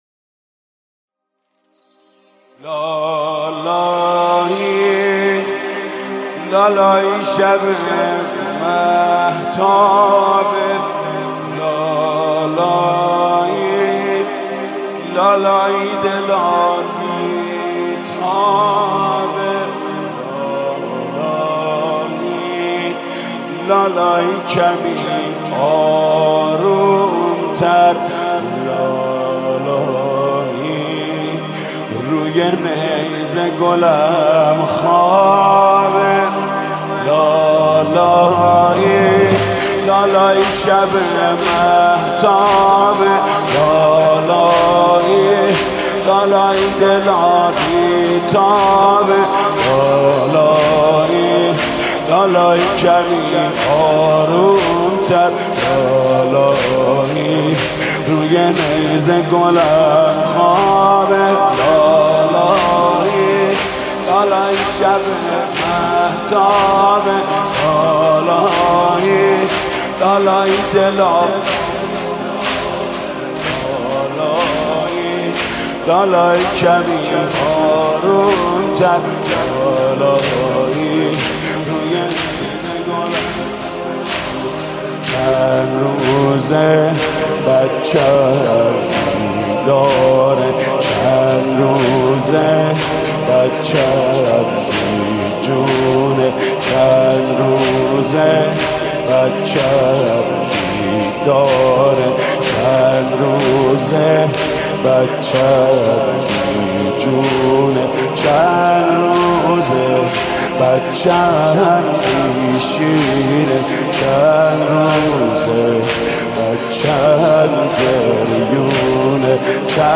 روضه شور سوزناک
با نوای دلنشین